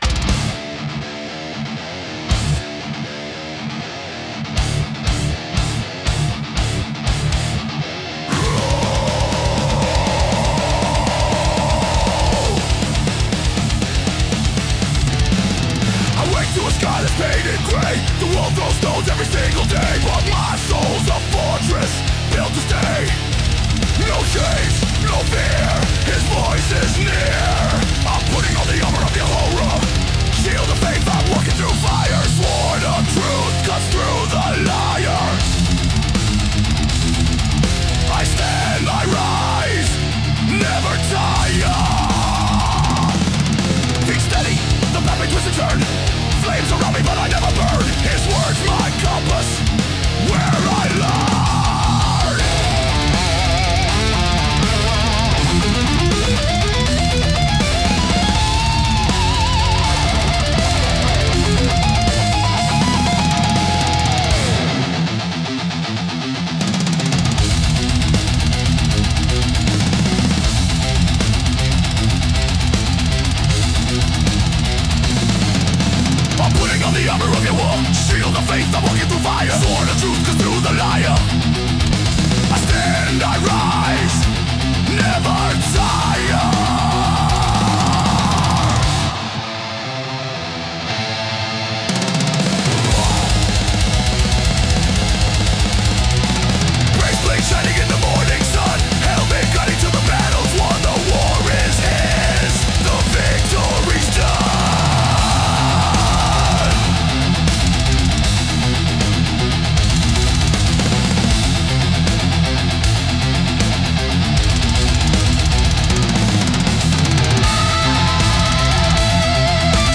Recorded in the basement of a dentist office. Raw.
Brutal sound. Unwavering faith.